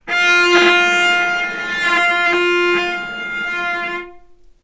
vc_sp-F4-ff.AIF